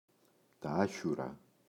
άχιουρο, το [‘açuro]